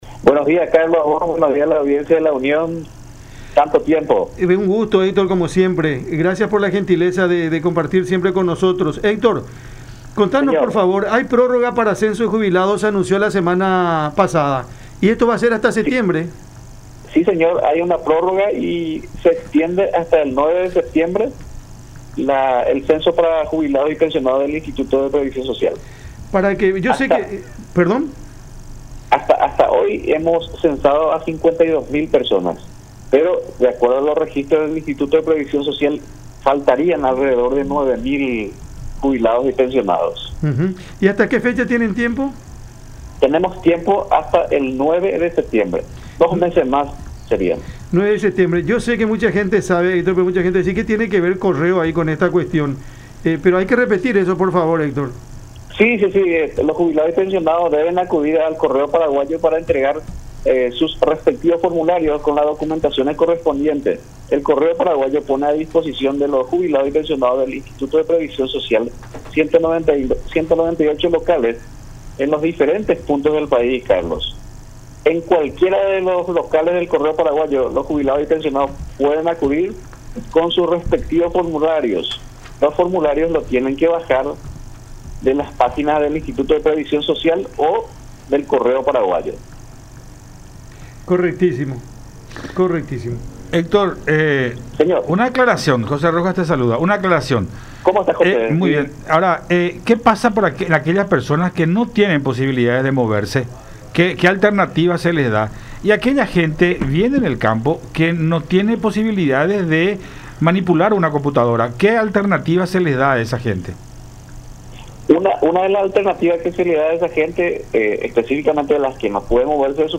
en conversación con Cada Mañana por La Unión.